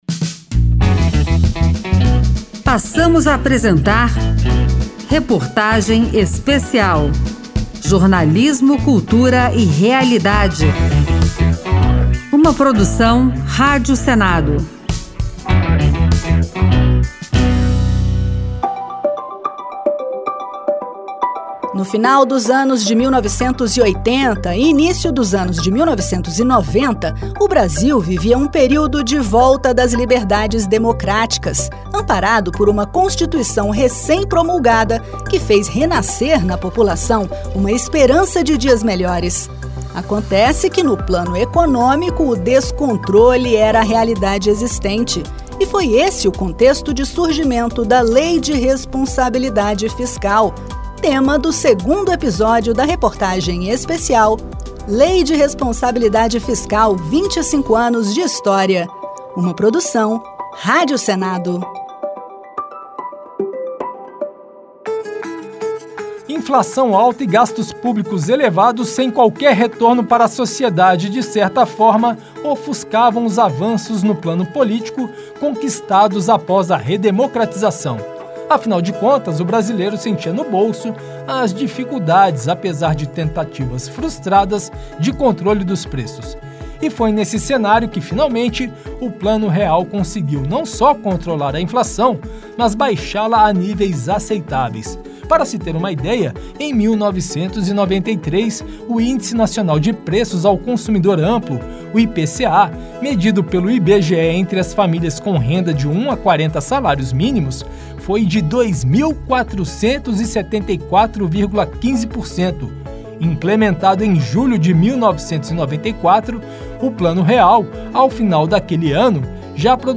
Reportagem Especial